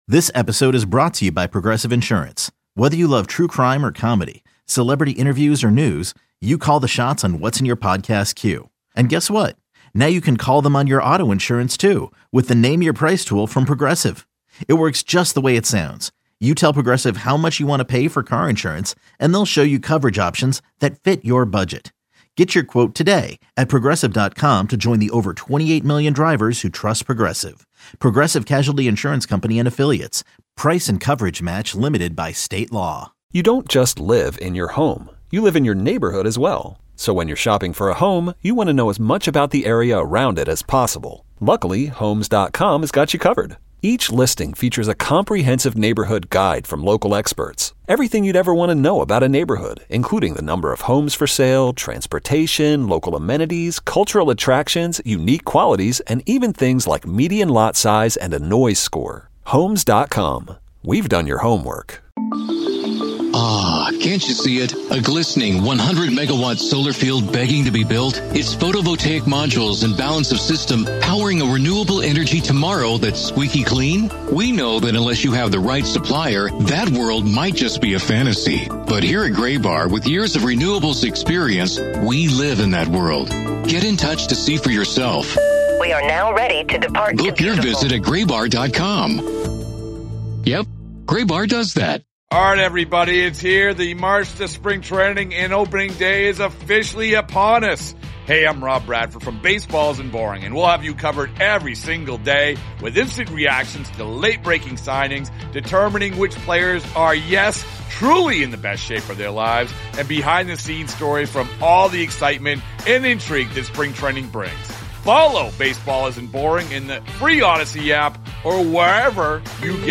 Reacting to Rafael Devers making clear that he doesn't want to move off of third base. Callers are furious, and Devers -- as well as Triston Casas -- have created a PR nightmare for the Sox.